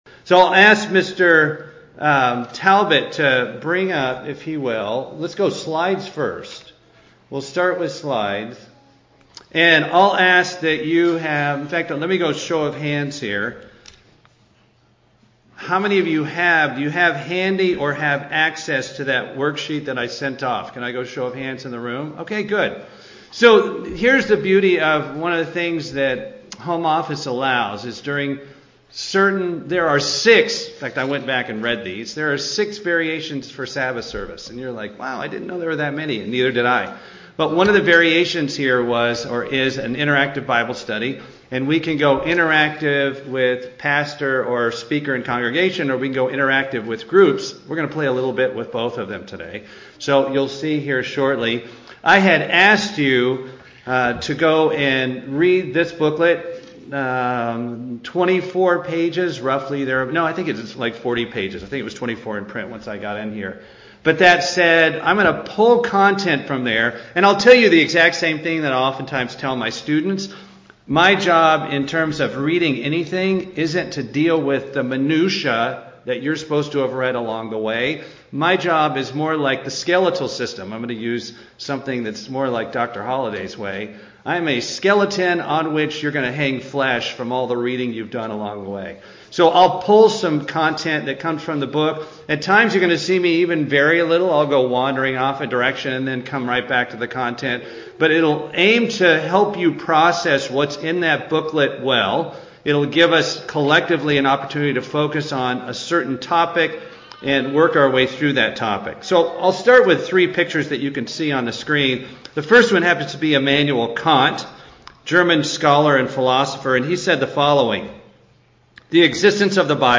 Bible Study
Given in Atlanta, GA Buford, GA